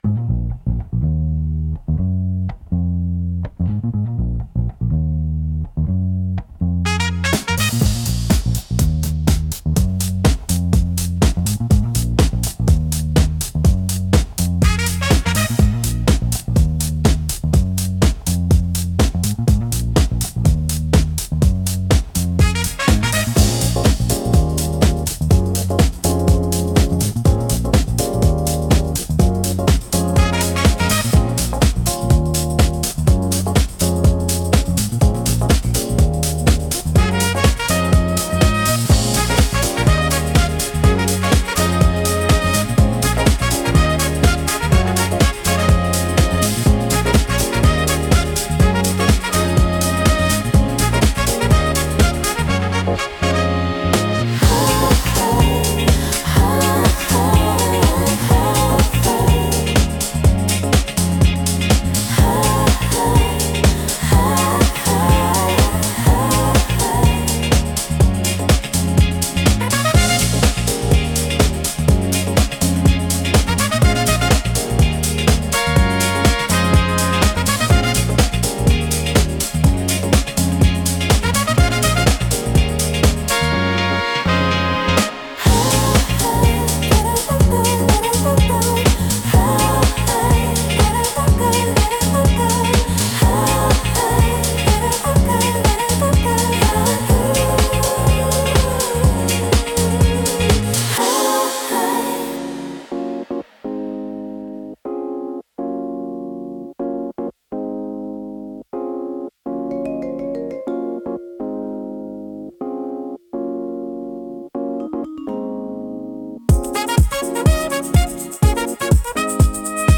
特に、ポジティブで元気な印象を与えたい時に適しています。